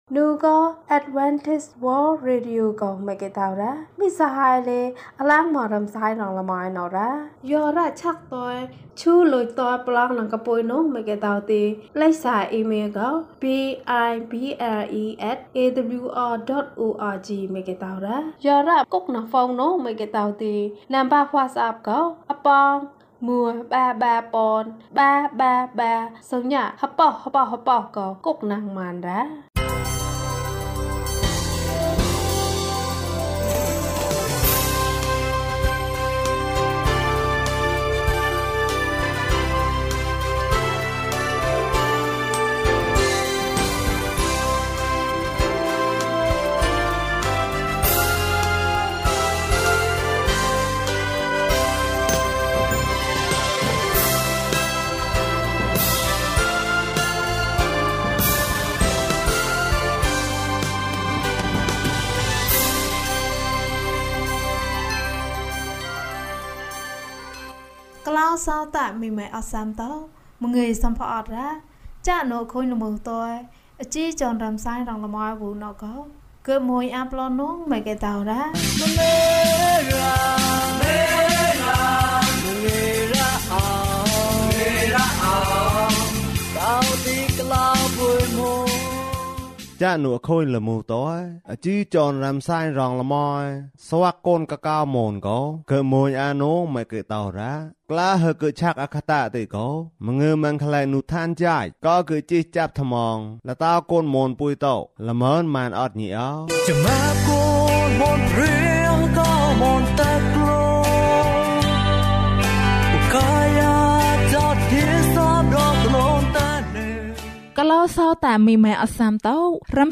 ပင်လယ်ထဲမှာ လမ်းလျှောက်။၀၂ ကျန်းမာခြင်းအကြောင်းအရာ။ ဓမ္မသီချင်း။ တရား‌ဒေသနာ။